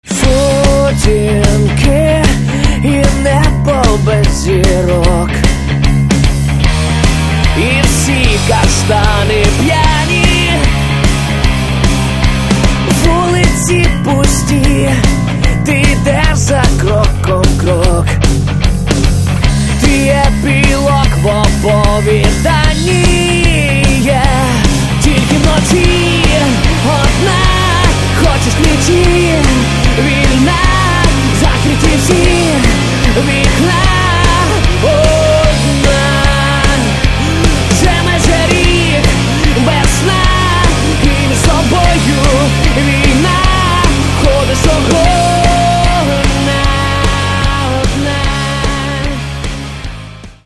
Каталог -> Рок та альтернатива -> Просто рок